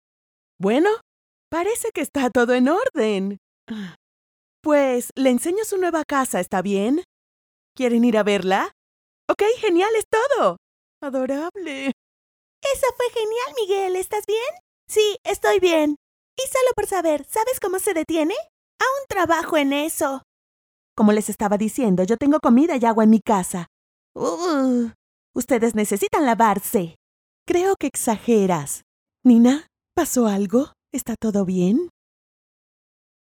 Female
Character, Confident, Conversational, Corporate, Friendly, Natural, Young
Demo_IVR_2024(1).mp3
Microphone: Audio-Technica AT4030a Cardioid Condenser Microphone